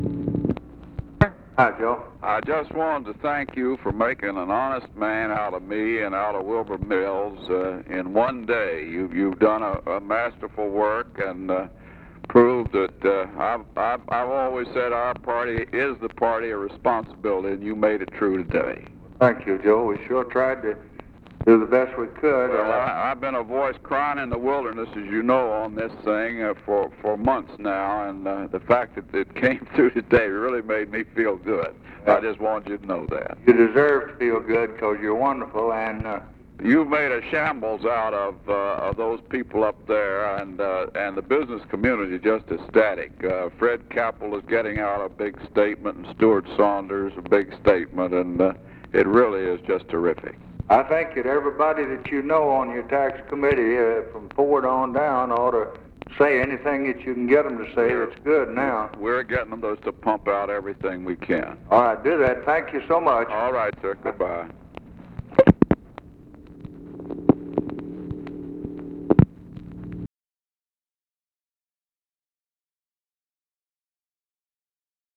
Conversation with HENRY FOWLER, January 8, 1964
Secret White House Tapes